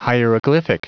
Prononciation du mot hieroglyphic en anglais (fichier audio)
Prononciation du mot : hieroglyphic